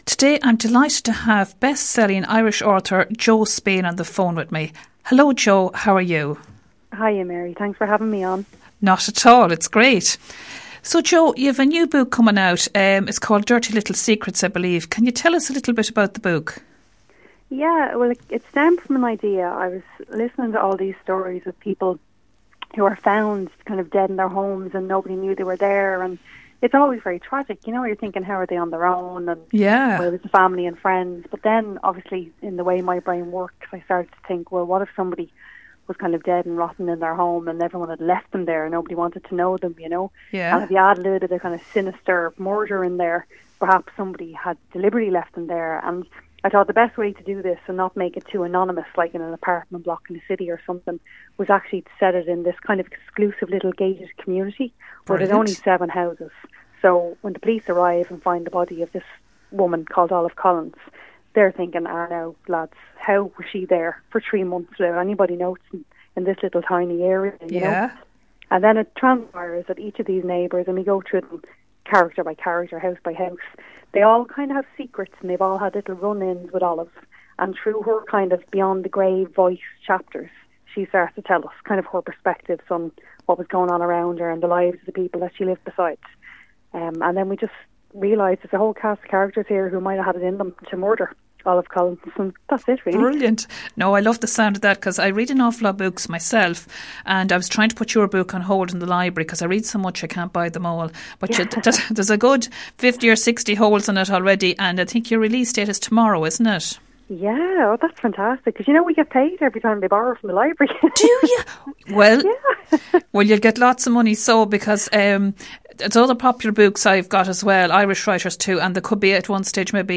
Best-Selling Author Jo Spain Interview - RosFM 94.6